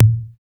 TICK TOM.wav